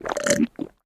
drink_honey3.ogg